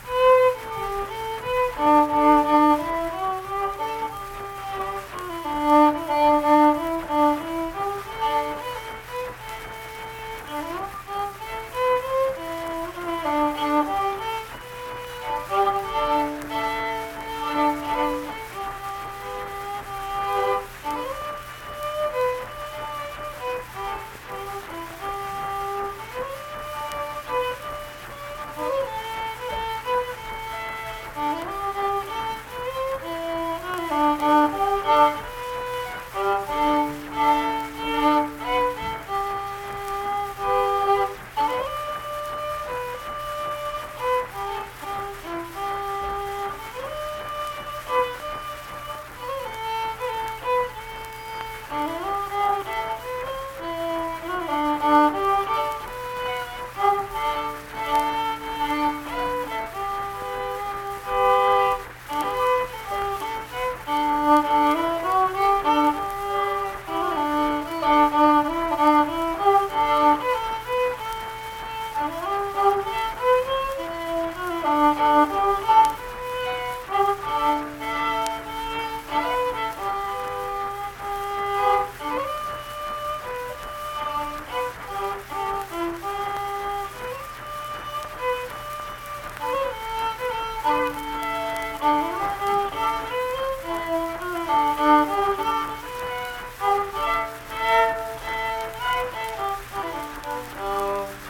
Unaccompanied fiddle music performance
Verse-refrain 3(2).
Instrumental Music
Fiddle